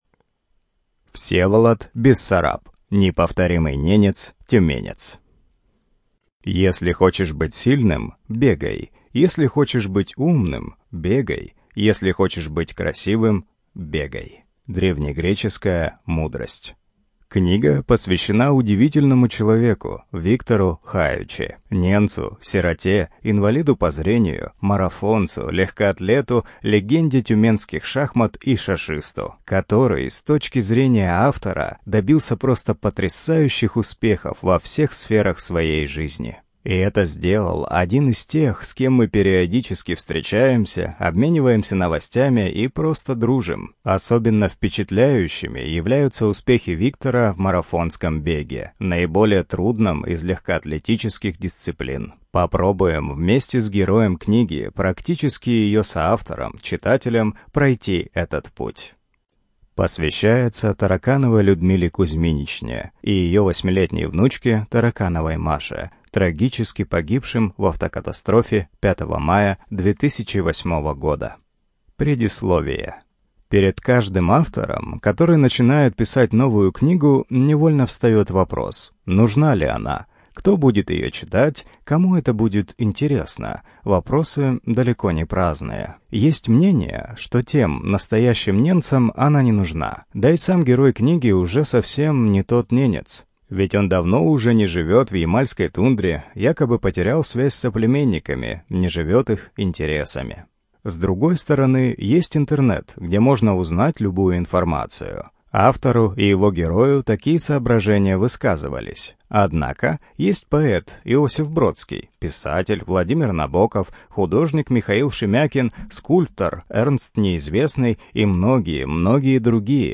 Студия звукозаписиТюменская областная библиотека для слепых